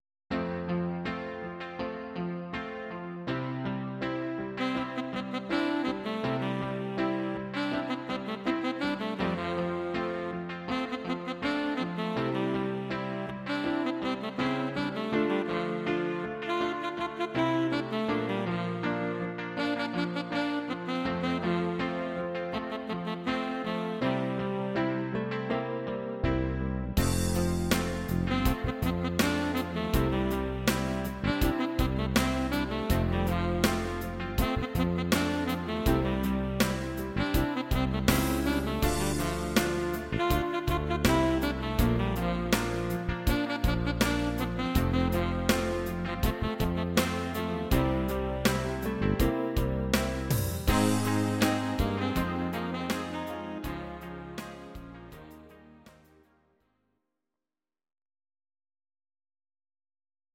These are MP3 versions of our MIDI file catalogue.
Please note: no vocals and no karaoke included.